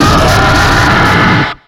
Cri de Charkos dans Pokémon X et Y.